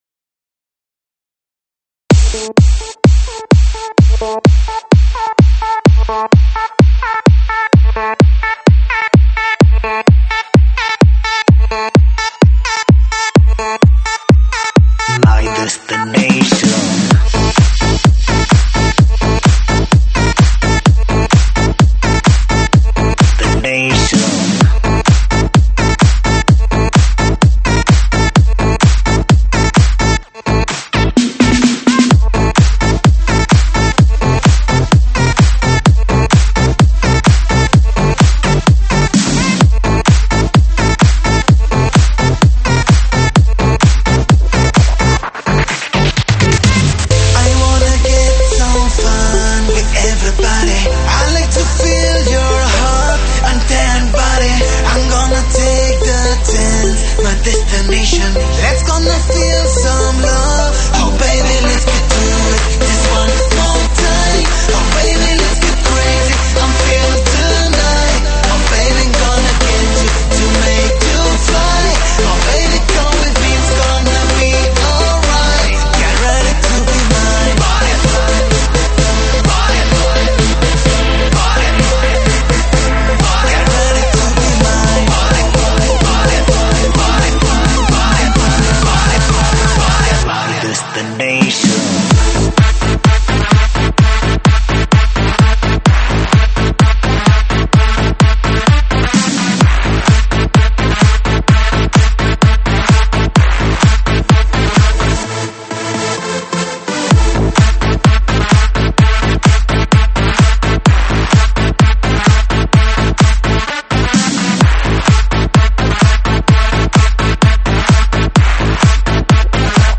栏目：英文舞曲